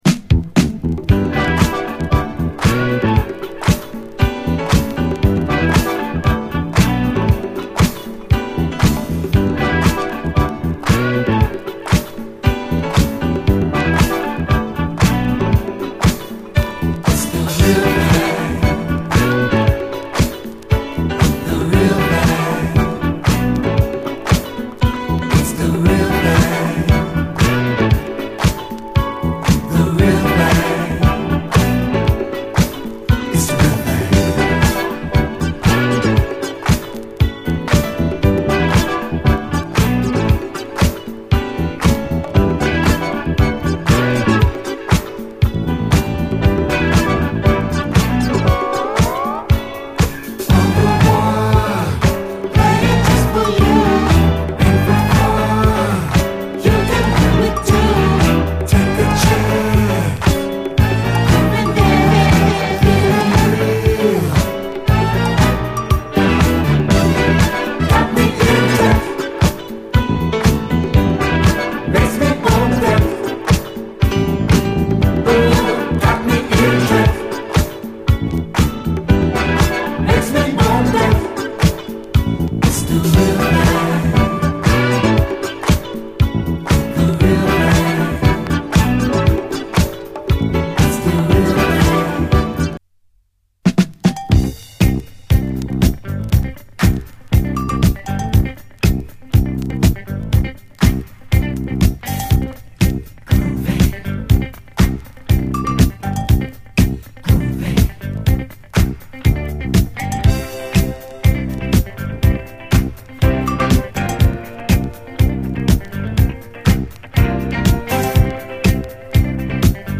R&B〜ソウル〜ラテン〜アフロなどを60’S末のサイケデリックな空気の元にミクスチャー！
フルートが甘く舞うメロウ・ファンク
STEREO、プロモ盤。